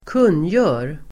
Uttal: [²k'un:jö:r]